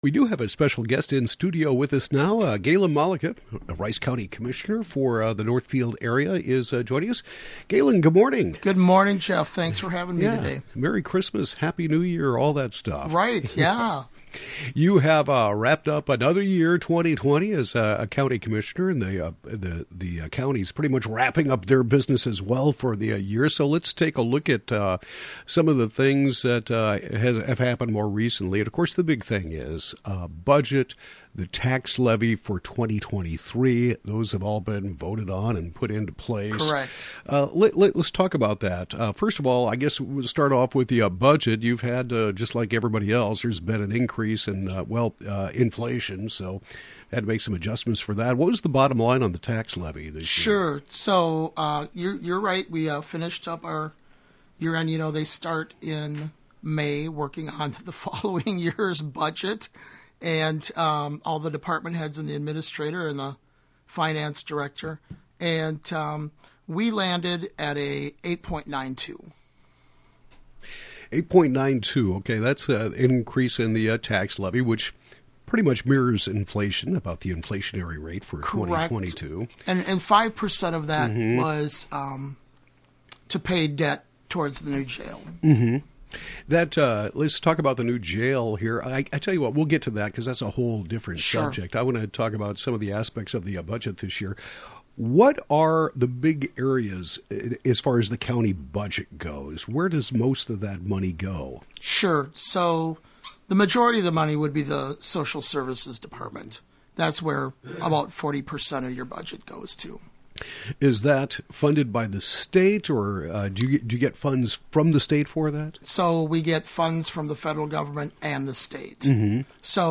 Rice County Commissioner Galen Malecha talks about the 2023 tax levy and budget, provides a Rice County jail construction update, and more.